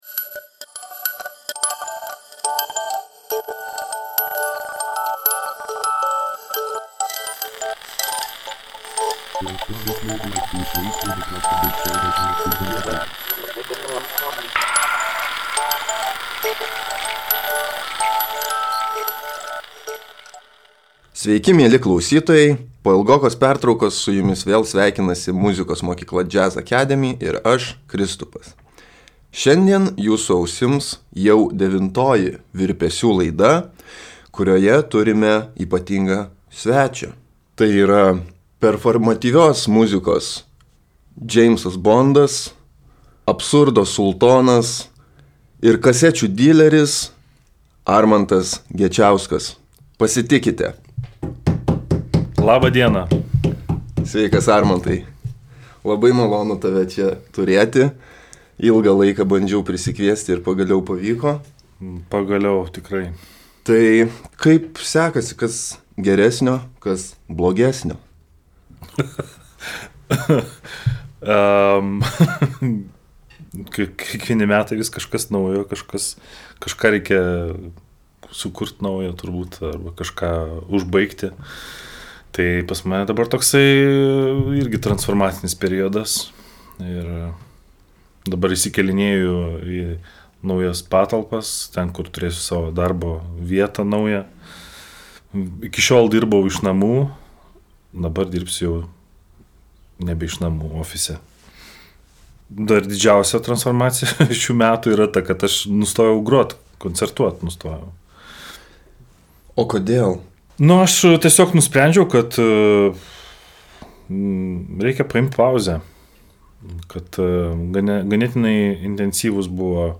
Pokalbis